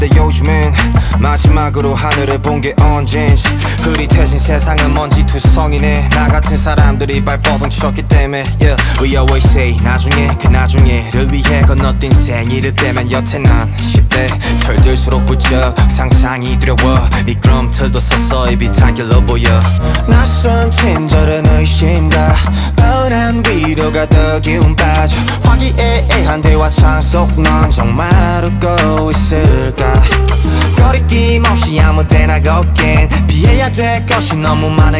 K-Pop Pop Hip-Hop Rap Korean Hip-Hop
Жанр: Хип-Хоп / Рэп / Поп музыка